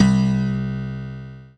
PIANO5-03.wav